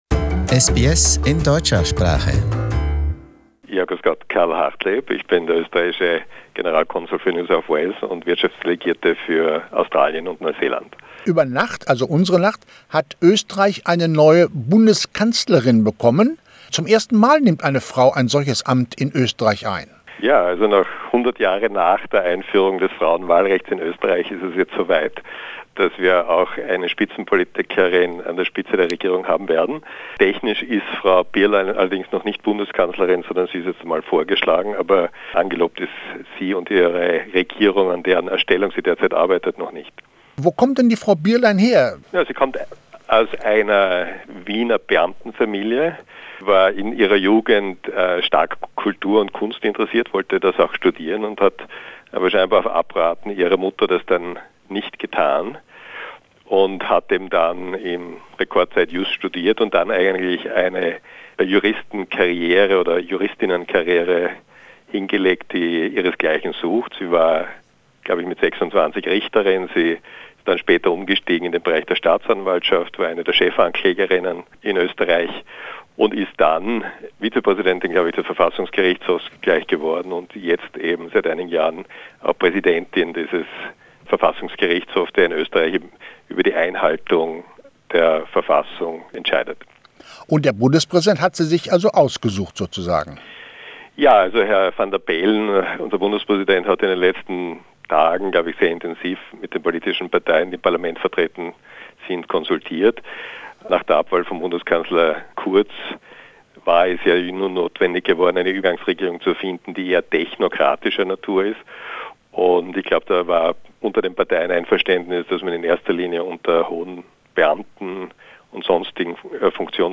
Karl Hartleb, Consul General of Austria in Sydney, tells SBS Radio listeners about the first woman at the helm of the government.